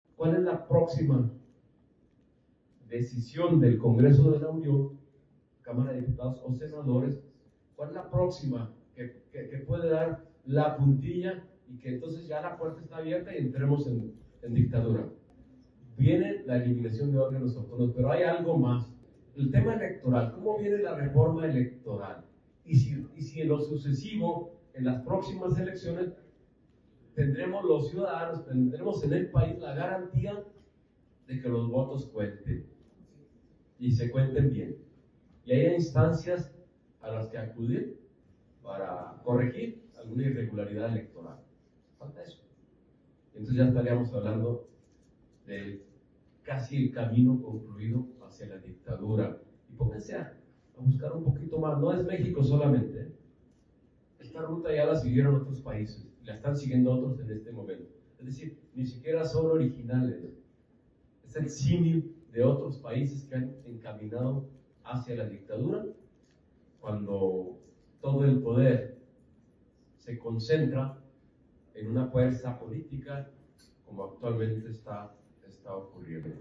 El senador por Chihuahua Mario Vázquez, sostuvo en su rueda de prensa que la administración federal de Morena, va en camino a una «dictadura«, pues dijo, inició con la reforma judicial donde se elegirá por voto popular a los juzgadores, continuó con la supremacía constitucional, y que culminaría si «desaparecen» al Instituto Nacional Electoral (INE), de reformar el presupuesto y cargos plurinominales.